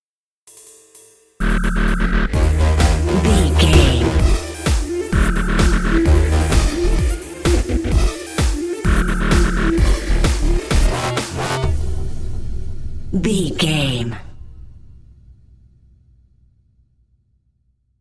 Aeolian/Minor
E♭
Fast
aggressive
dark
groovy
futuristic
industrial
frantic
drum machine
synthesiser
breakbeat
energetic
synth leads
synth bass